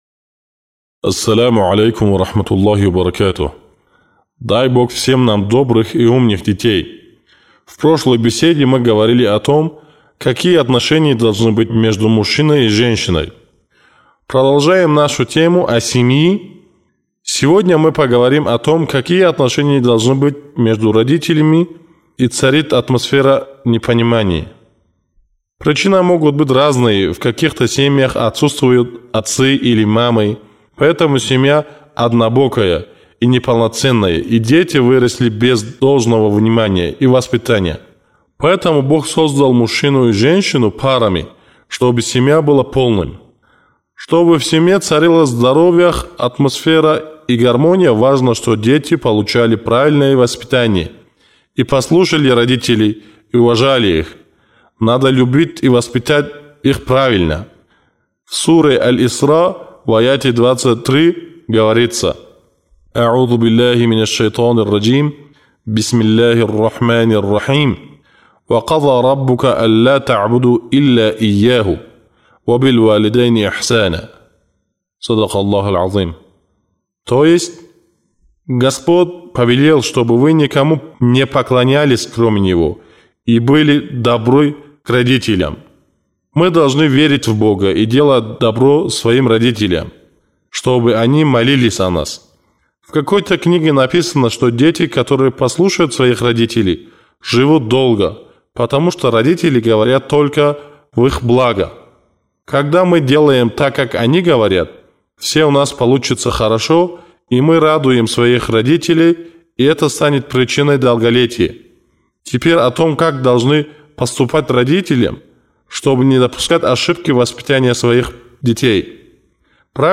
Чтобы найти ответы на все эти вопросы, вы можете прослушать одиннадцатую речь «Ребенок-благодетель».